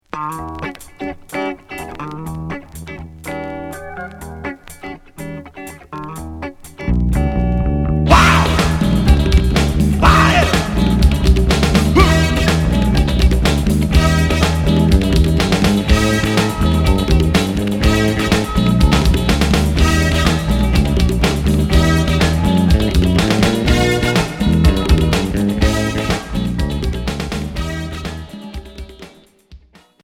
Rubrique rock LILLE